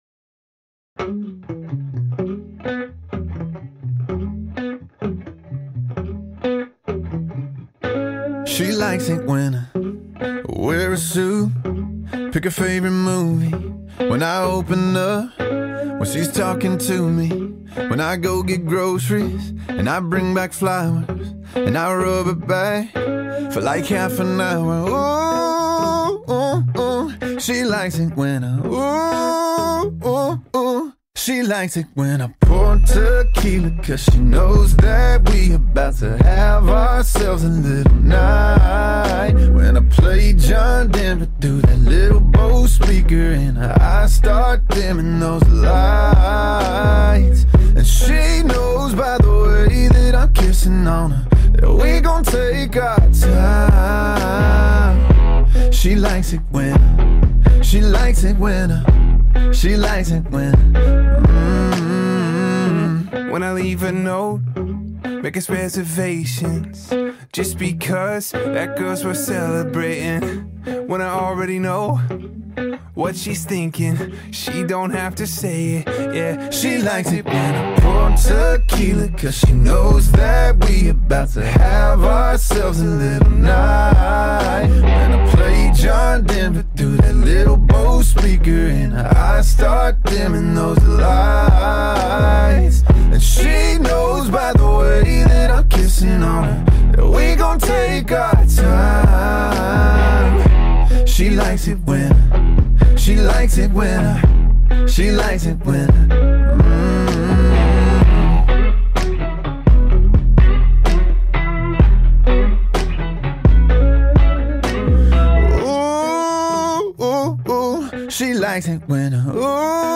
country pop